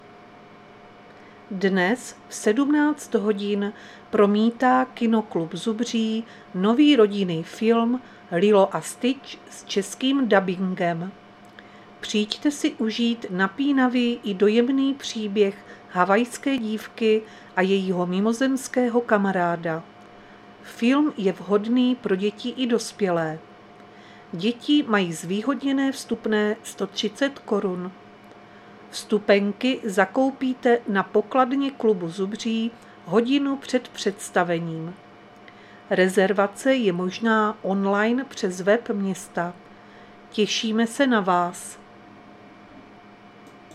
Záznam hlášení místního rozhlasu 28.5.2025
Zařazení: Rozhlas